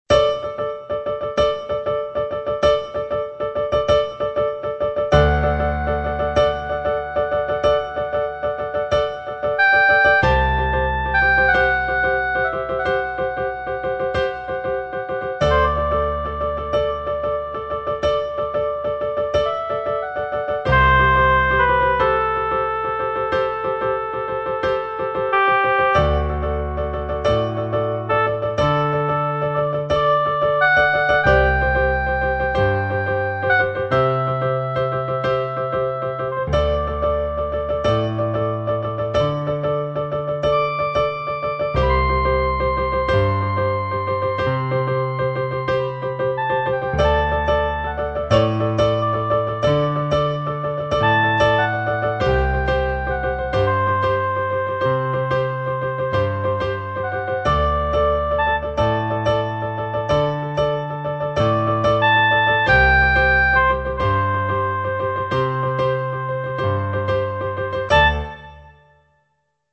Gypsy Swing Duet